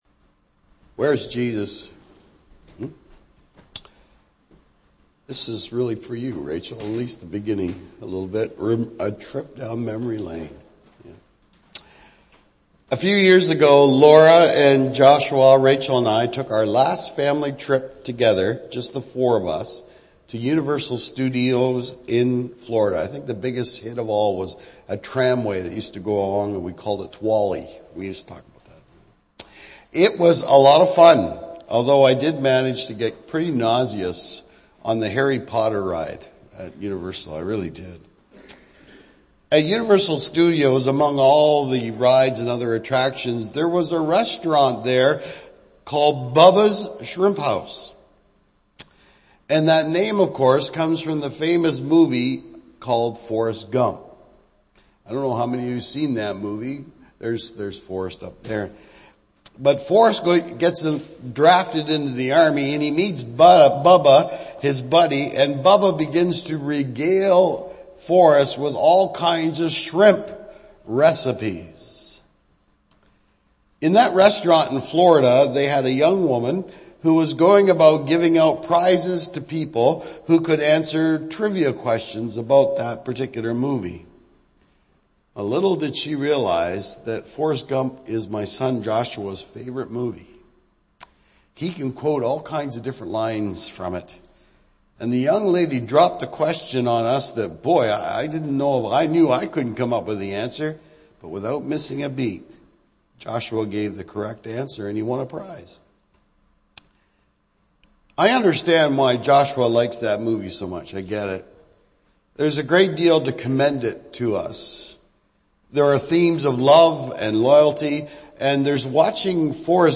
Archived Sermons
Date: April 20 th , 2025 – Easter Sunday Title : Where is Jesus?